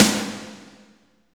48.07 SNR.wav